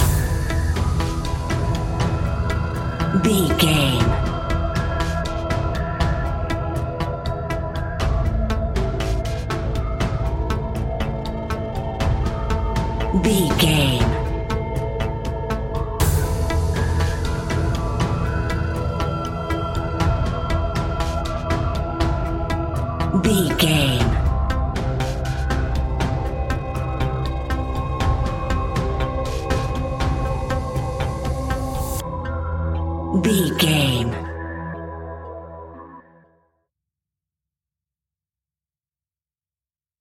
Aeolian/Minor
ominous
haunting
eerie
synthesizer
drum machine
tense
ticking
electronic music
electronic instrumentals